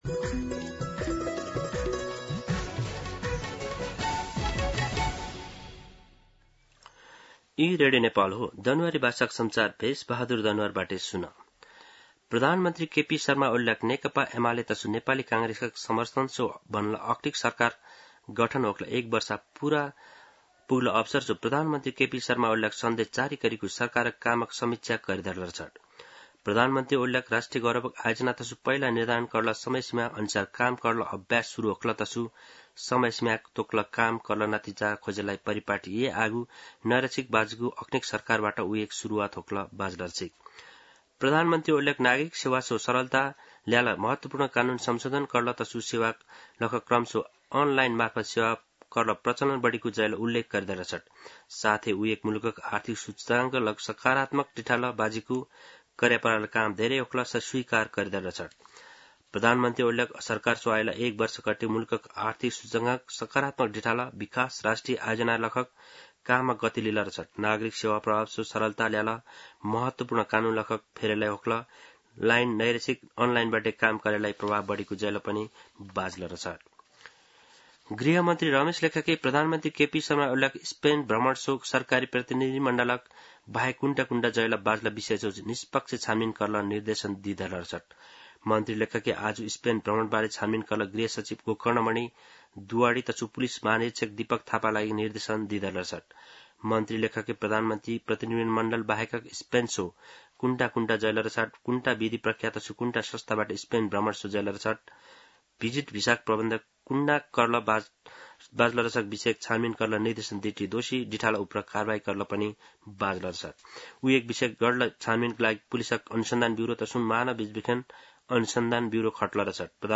दनुवार भाषामा समाचार : ३० असार , २०८२